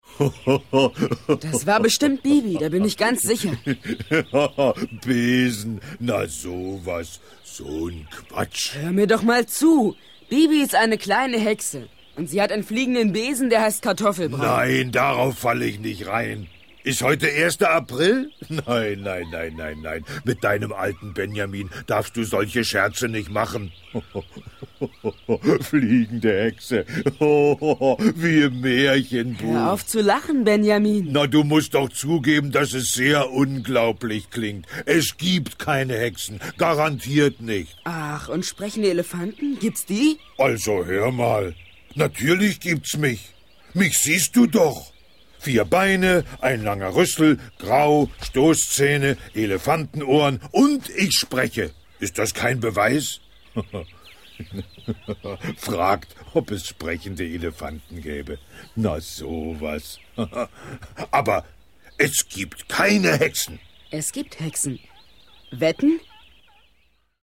Ravensburger Benjamin Blümchen - ...und Bibi Blocksberg ✔ tiptoi® Hörbuch ab 3 Jahren ✔ Jetzt online herunterladen!